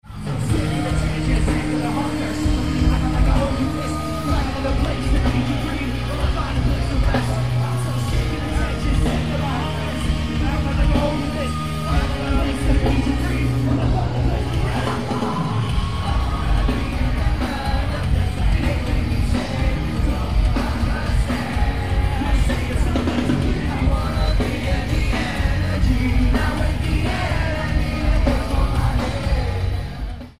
Venue:Roseland Ballroom
Venue Type:Music/Concert Hall